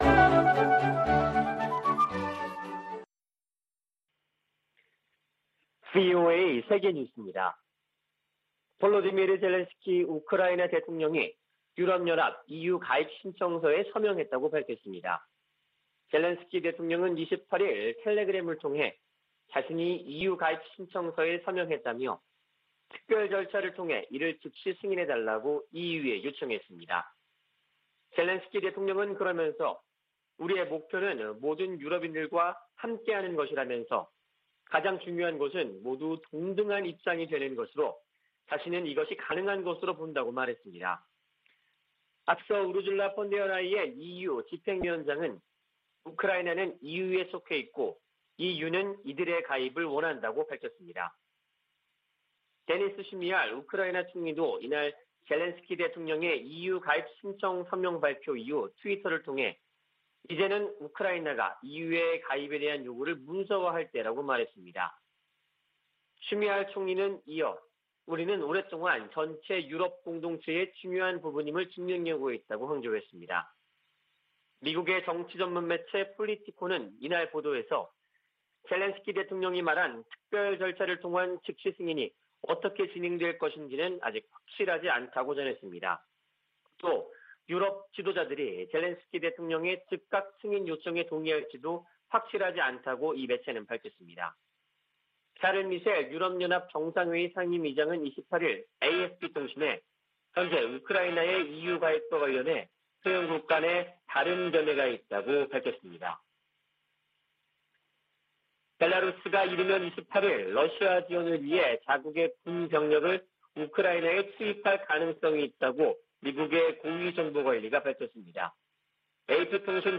VOA 한국어 아침 뉴스 프로그램 '워싱턴 뉴스 광장' 2022년 3월 1일 방송입니다. 북한은 27일 발사한 준중거리 탄도미사일이 정찰위성에 쓰일 카메라 성능을 점검하기 위한 것이었다고 밝혔습니다. 미 국무부는 북한의 탄도미사일 시험 발사 재개를 규탄하고 도발 중단을 촉구했습니다. 미한일 외교∙안보 고위 당국자들이 전화협의를 갖고 북한의 행동을 규탄하면서 3국 공조의 중요성을 거듭 강조했습니다.